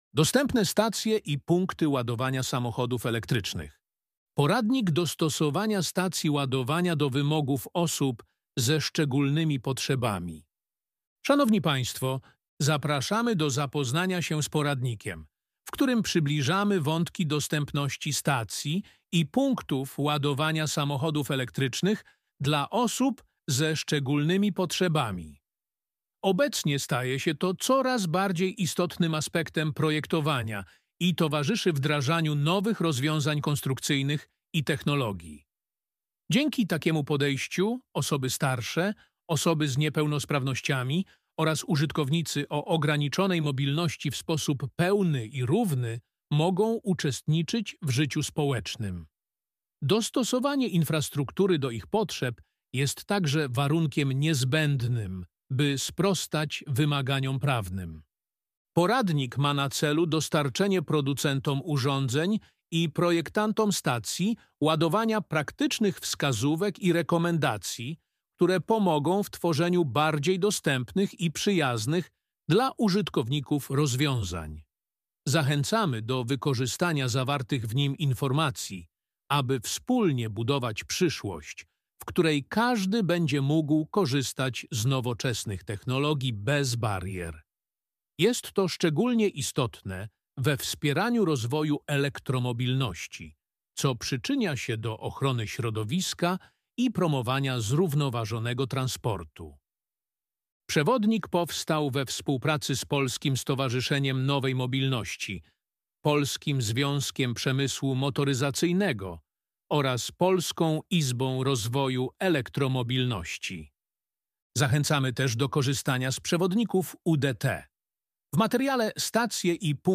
Audiobook_DOSTEPNE_STACJE_EV.mp3